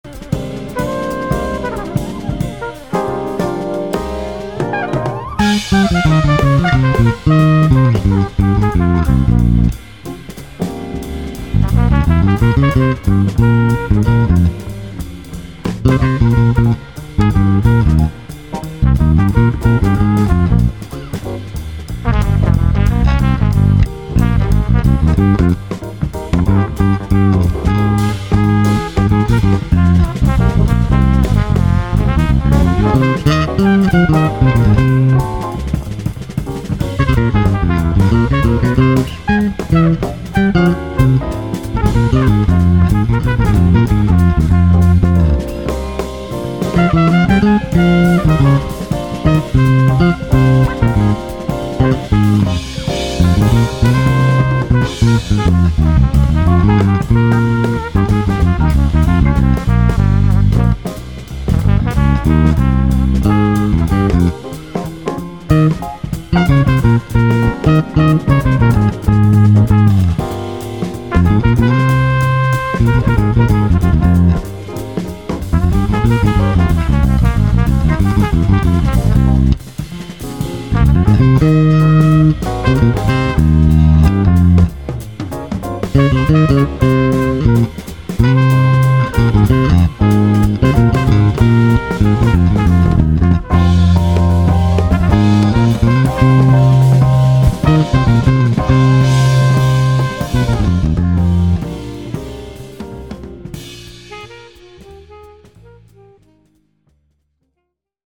Jetzt gibt es den MC-924 auch zu hören
im Originaltempo